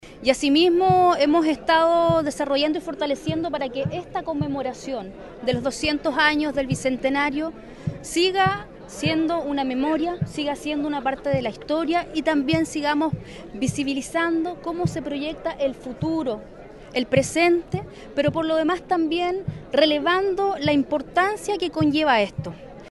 El Bicentenario de Chiloé se conmemoró en el Fuerte San Antonio de Ancud, región de Los Lagos, donde autoridades civiles y militares, representantes huilliches y distintas organizaciones se reunieron para recordar la historia del archipiélago y analizar sus desafíos actuales.
Por su parte, la delegada Regional de Los Lagos, Paulina Muñoz, valoró el sentido histórico de la conmemoración.